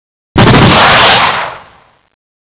shootb.wav